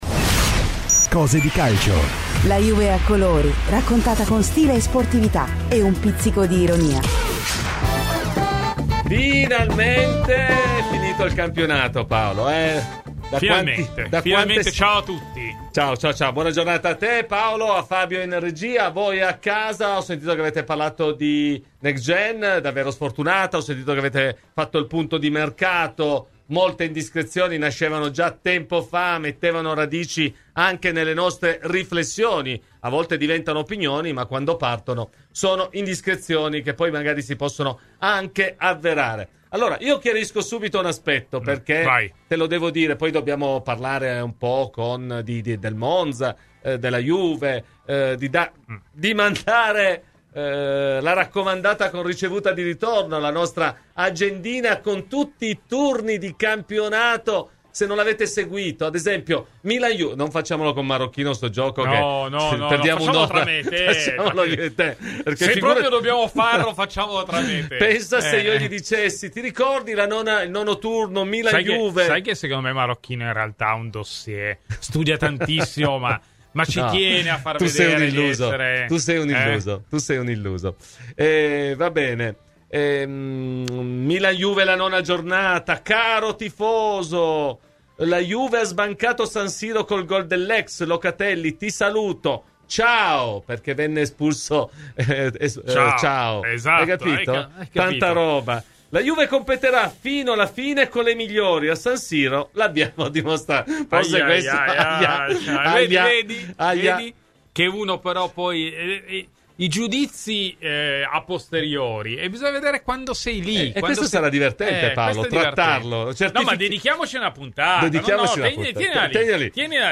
Due parentesi vissute in bianconero, prima tra il 1983 e l'85, poi tra il 1986 e l'88, Beniamino Vignola è stato oggi ospite di "Cose di Calcio" su Radio Bianconera , per parlare dell'addio burrascoso tra Allegri e la Juventus: